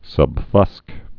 (sŭb-fŭsk)